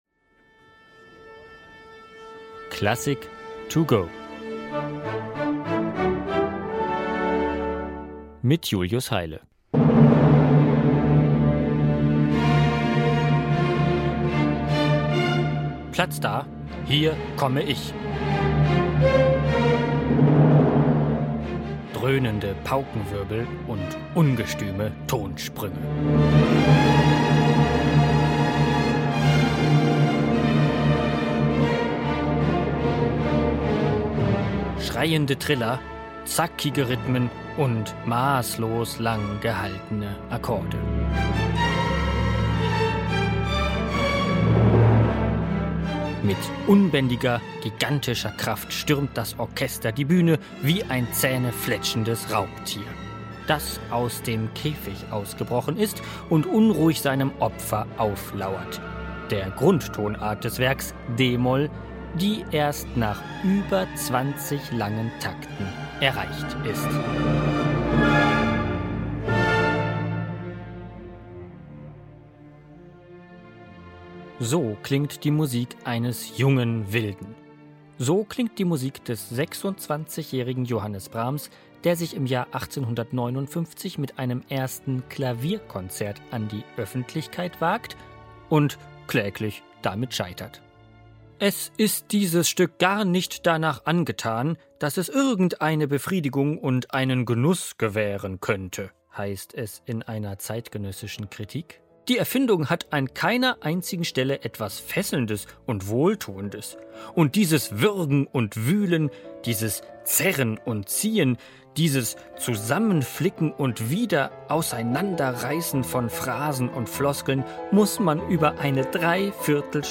In dieser Werkeinführung